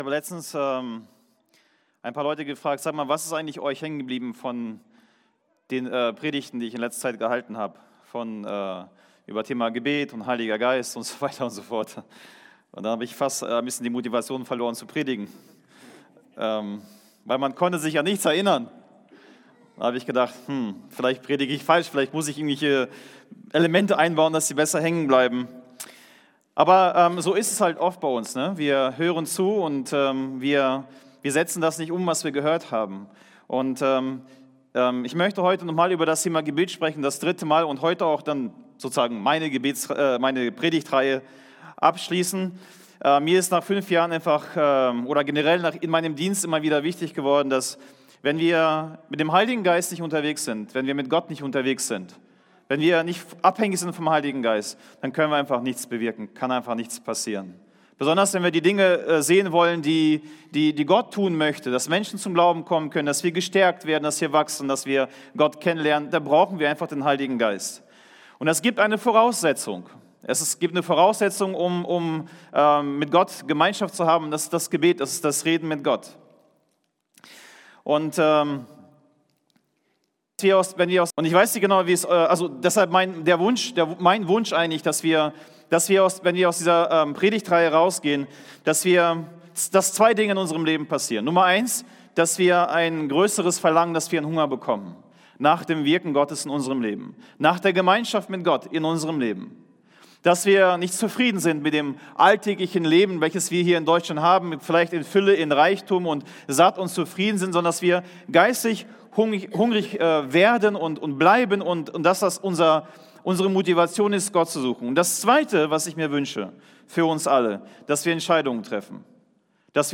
Radikal: zurück zu Jesus – Casinogemeinde Predigten – Podcast